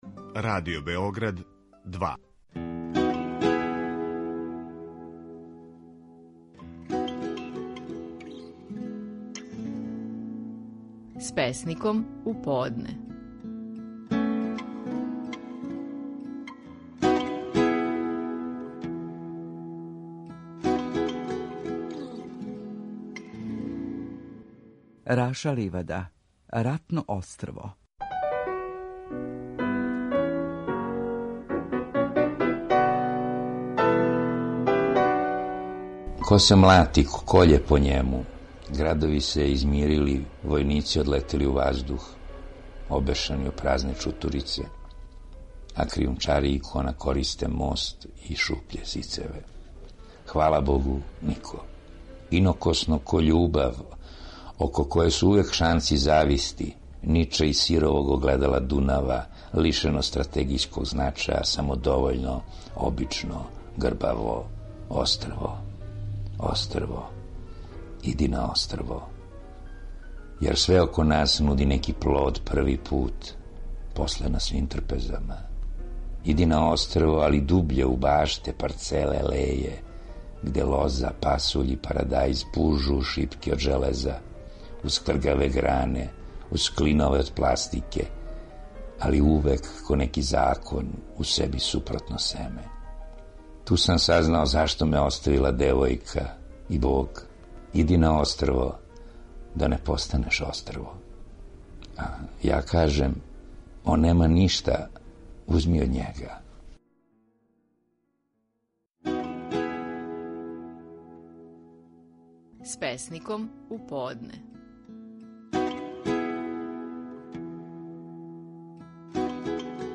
Стихови наших најпознатијих песника, у интерпретацији аутора.
Раша Ливада говори песму „Ратно острво".